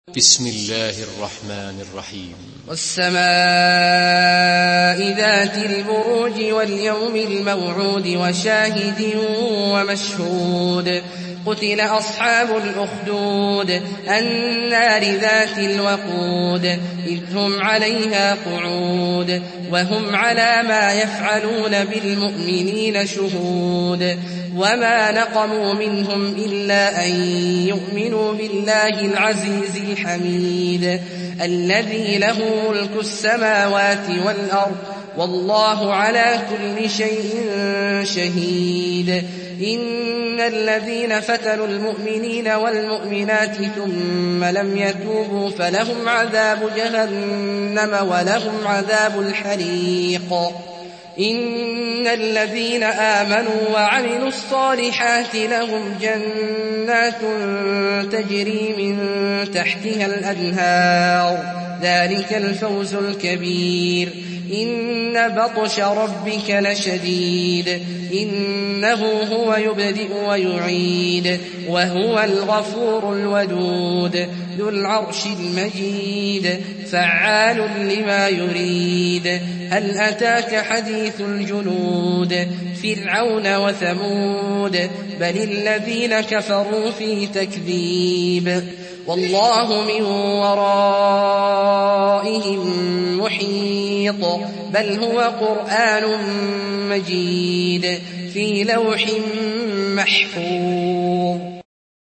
Surah Büruc MP3 in the Voice of Abdullah Al-Juhani in Hafs Narration
Murattal Hafs An Asim